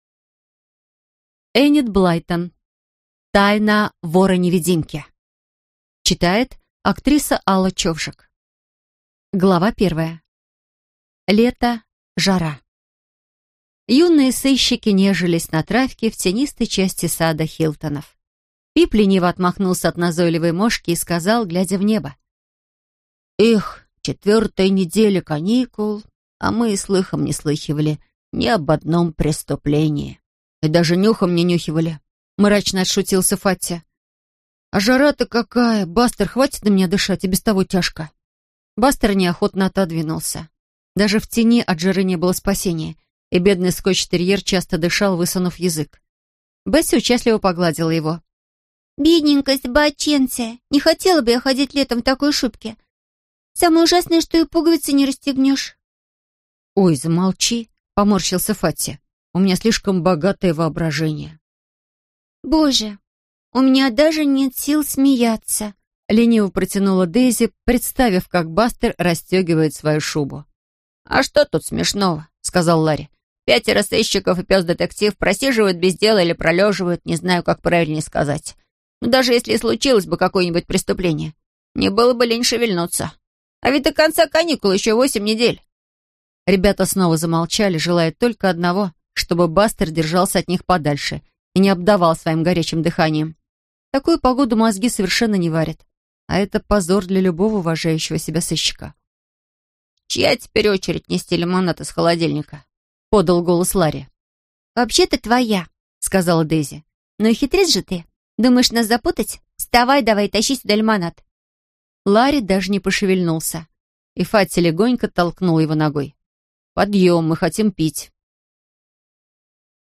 Аудиокнига Тайна вора-невидимки | Библиотека аудиокниг
Прослушать и бесплатно скачать фрагмент аудиокниги